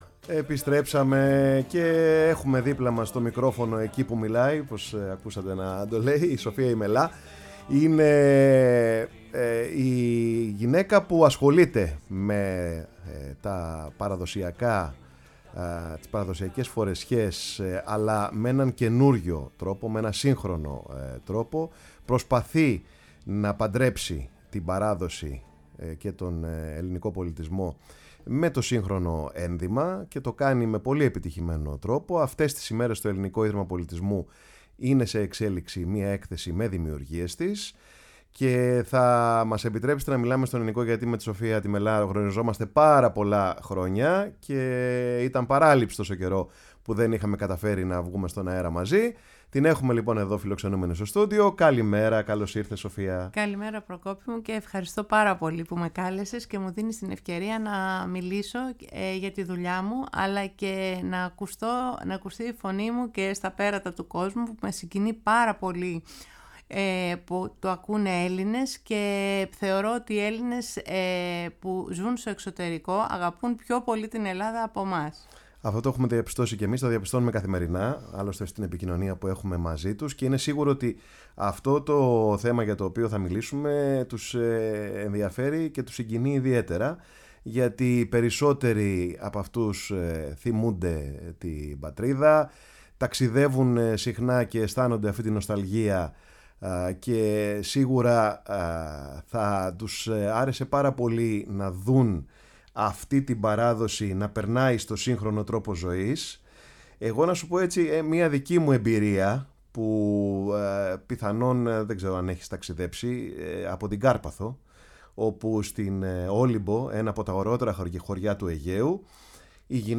Στο δεύτερο ημίωρο της εκπομπής “Πάρε τον Χρόνο σου” στο στούντιο της Φωνής της Ελλάδας βρέθηκε